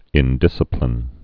(ĭn-dĭsə-plĭn)